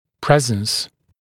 [‘prezns][‘преэзнс]присутствие, наличие